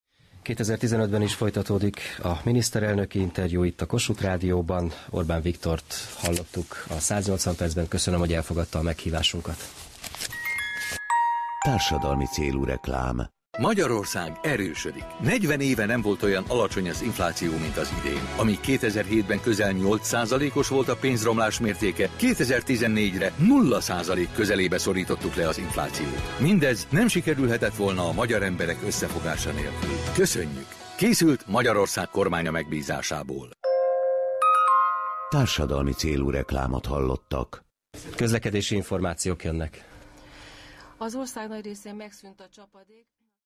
Hallgasd meg, hogyan lett vége, milyen “társadalmi célú” reklámot adtak be a szerkesztők, hogy Orbán Viktor jókedvűen távozzon a stúdióból.